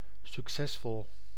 Ääntäminen
IPA : /ˈprɒsp(ə)rəs/